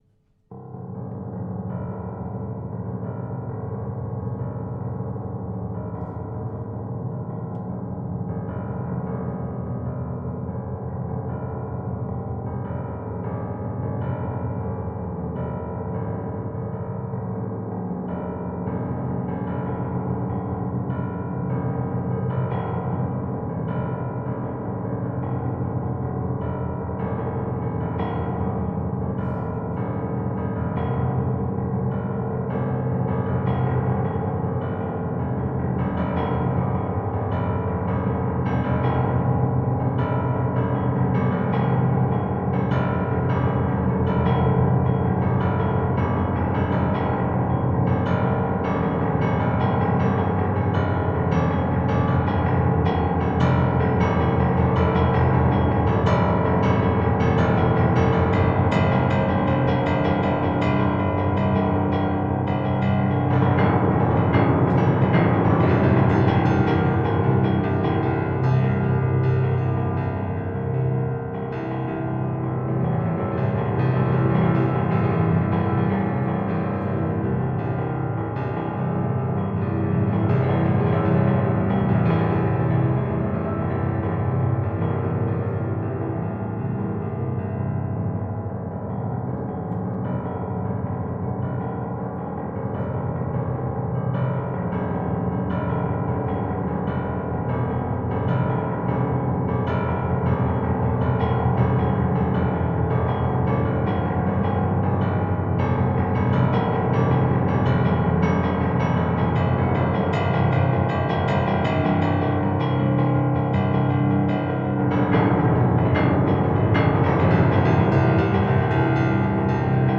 live
Oberheim OB-8